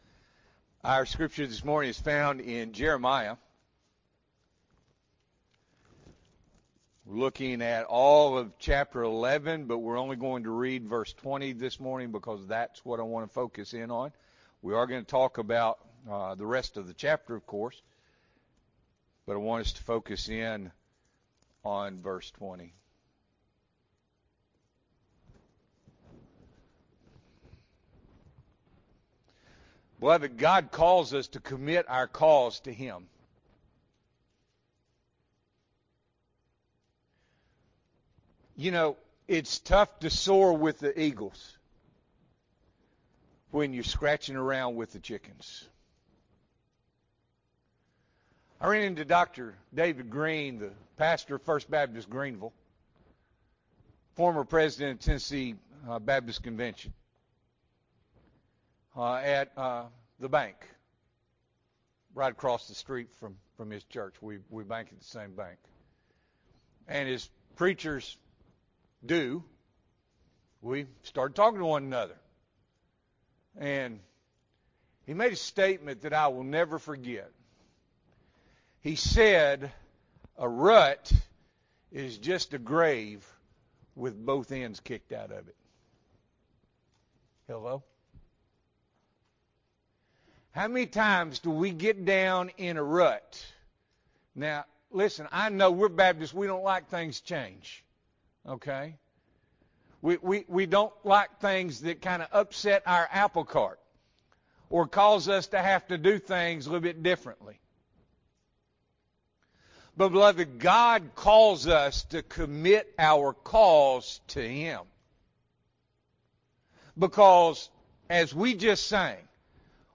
August 25, 2024 – Morning Worship